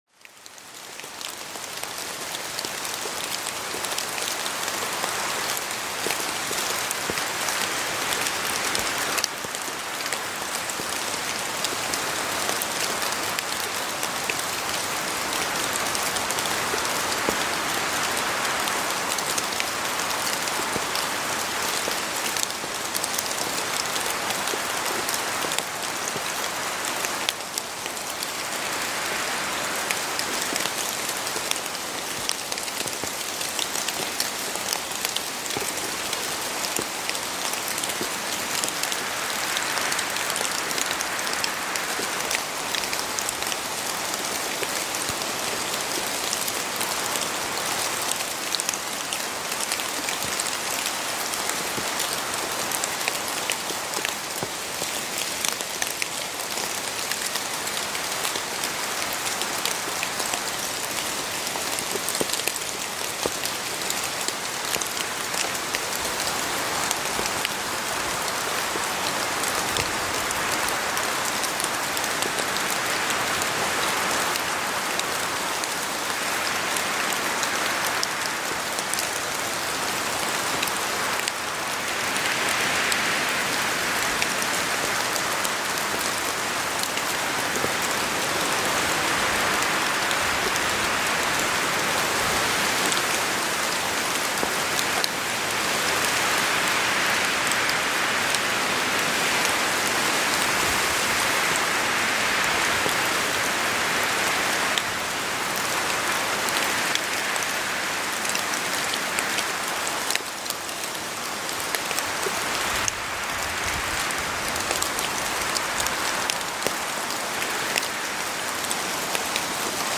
SOFORT-ENTSPANNUNG: Waldregen-Meditation mit Tropfen + Wind